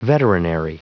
Prononciation du mot veterinary en anglais (fichier audio)
Prononciation du mot : veterinary
veterinary.wav